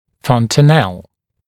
[ˌfɔntəˈnel][ˌфонтэˈнэл]родничок (неокостеневший участок свода черепа)